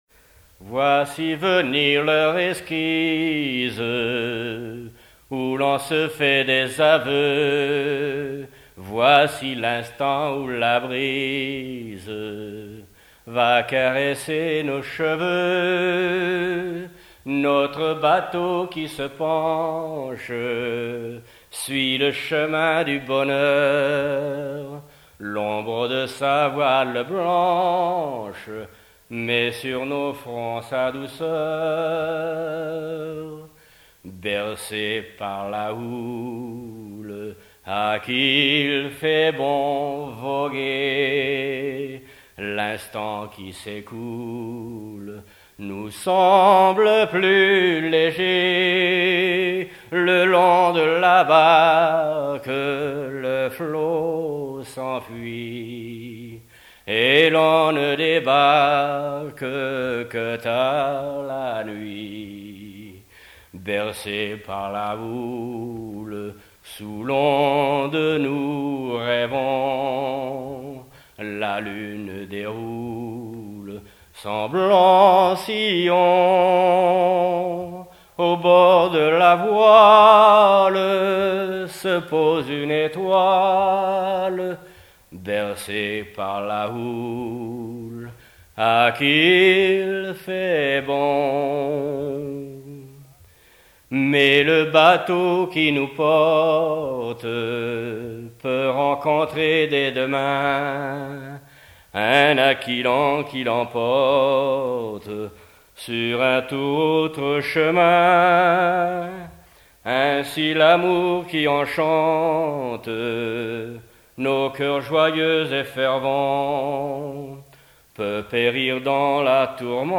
Enquête Musée des Arts et Traditions Populaires
chansons tradtionnelles
Pièce musicale inédite